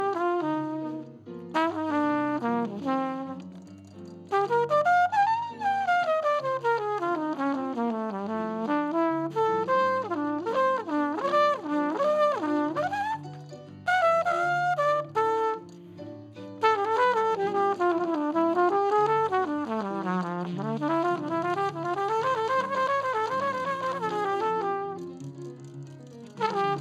Hallo, hat jemend vielleicht eine Idee wie man die Klappengeräusche (hier im Bsp. Trompete) im Mix nachträglich irgendwie etwas reduzieren kann? Es handelt sich um eine Konzertspur, Trompete wurde aufgenommen mit einem Sennheiser MD421. Ich versuche das schon seit ein paar Stunden, aber irgendwie wird bei allen Versuchen der Sound immer nur dumpfer, was ich eigentlich nicht möchte.